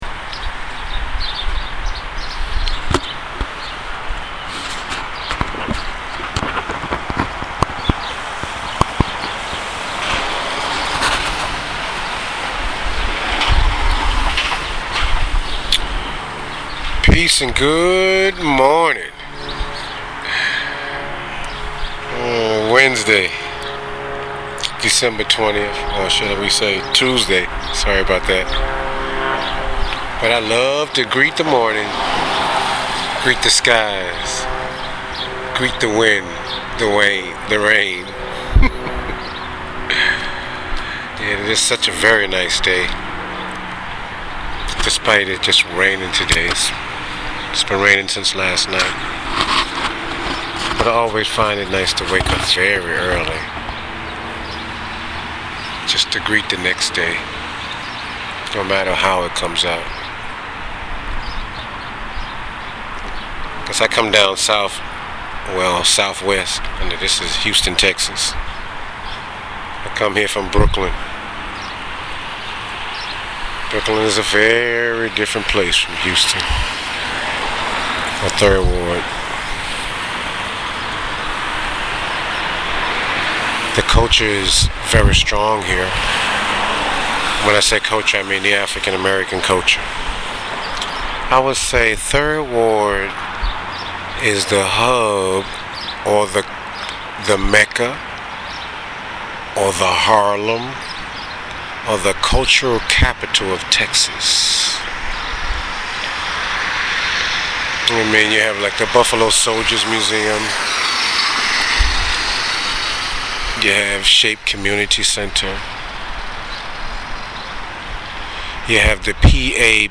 on a rainy day in Houston